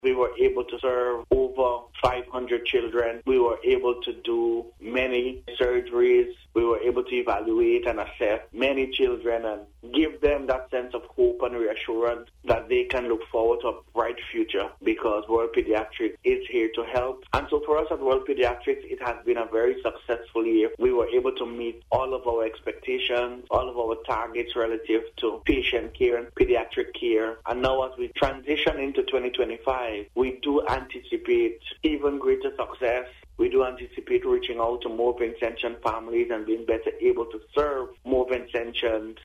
during an interview with NBC News.